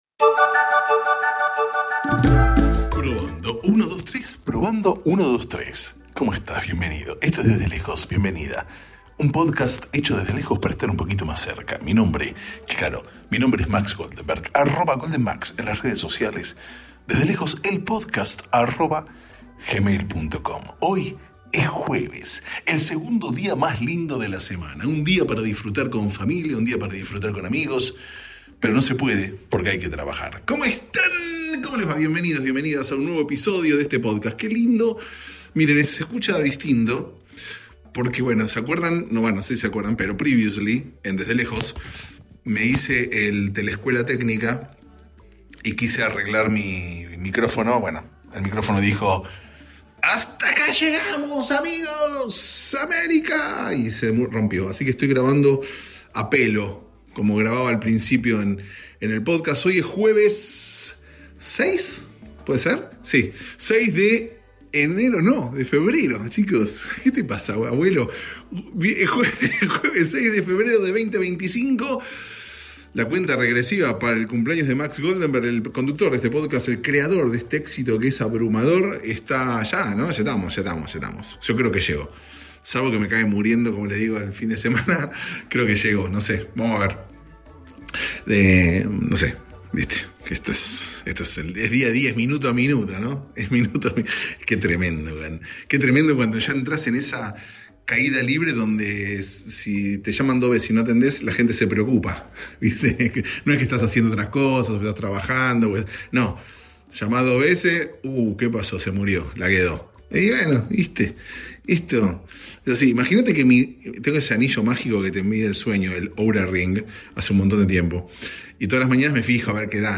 Aquí nos divertimos, escuchamos música y hablamos con gente interesante, a la distancia.